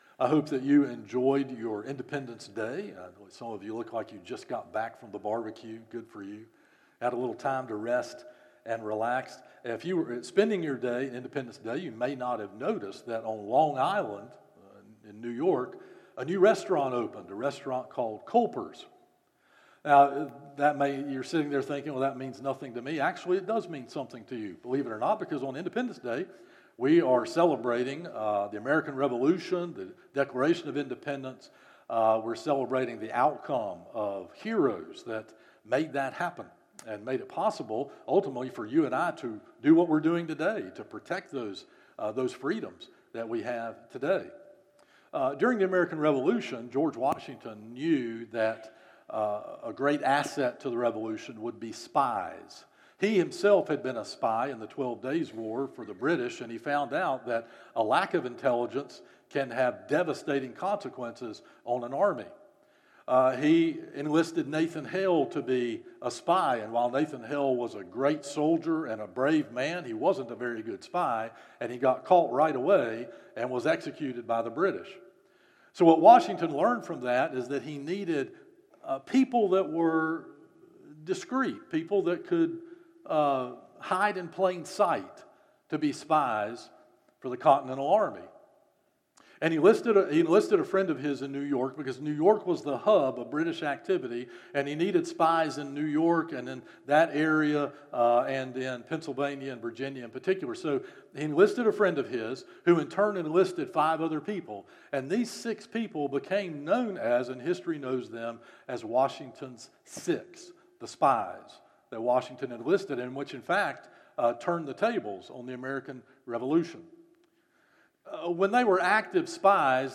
Morning Worship - 11am Passage